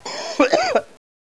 cough2.wav